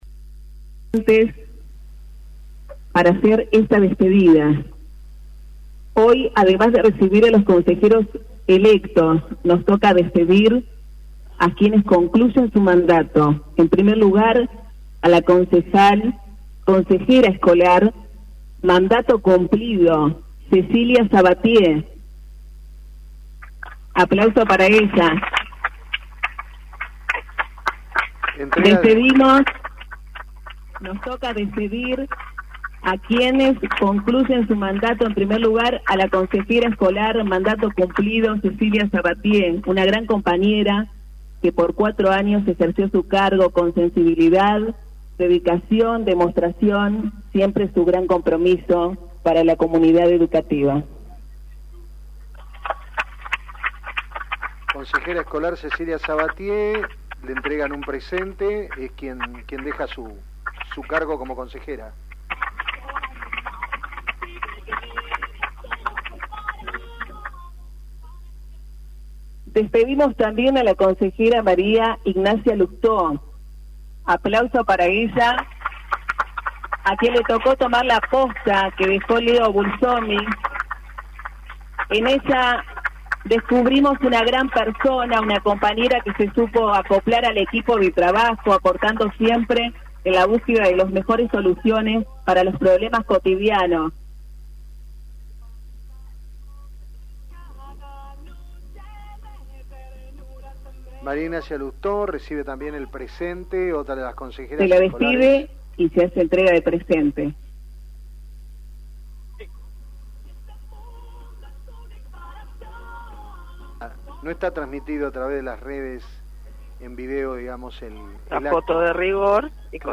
Este mediodía en la vereda del Consejo Escolar Las Flores se llevo a cabo el acto de asunción de nuevos consejeros elegidos en las elecciones de septiembre pasado.